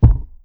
big-thud-2.wav